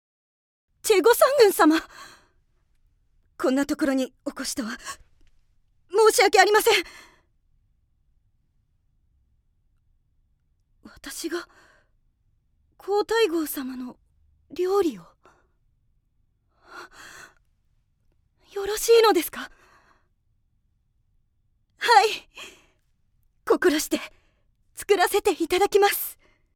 ◆外画・宮廷女官◆